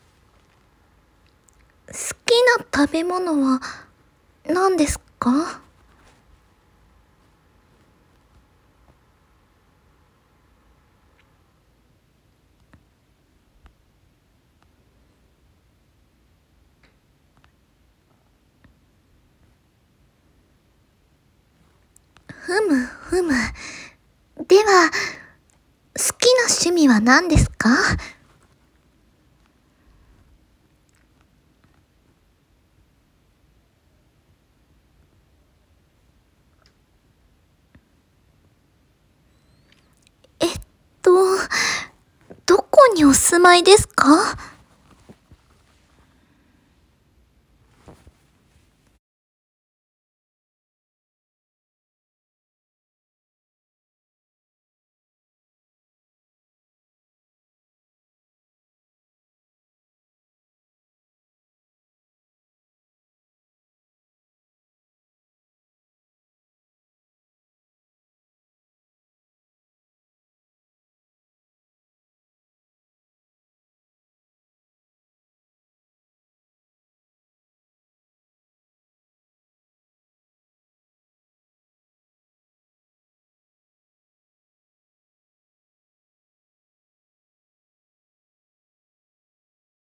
トネリコ(似てない)がインタビューするだけ